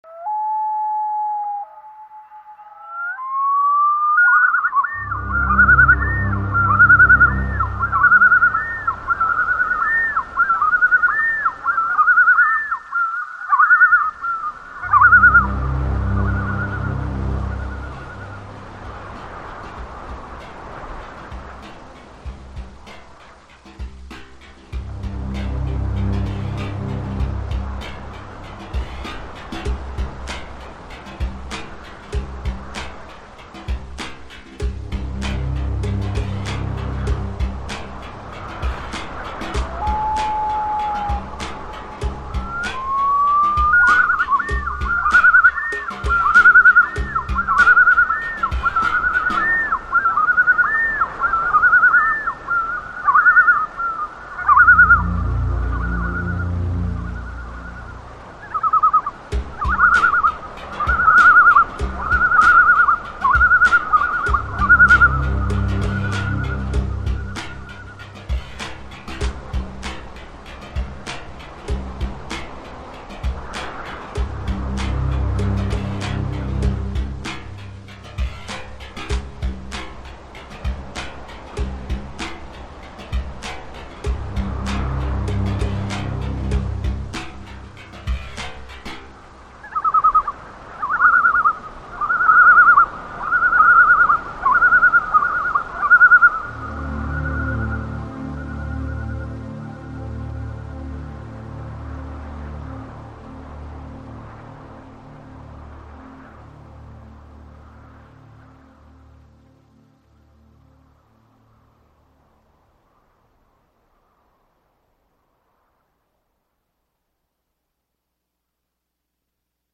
1. Environmental sounds related to the historic period
3. Composed musical sequences.